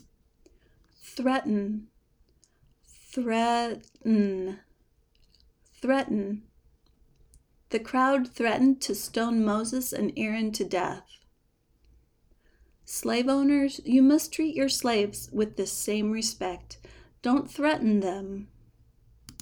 ˈθrɛ tən  (verb)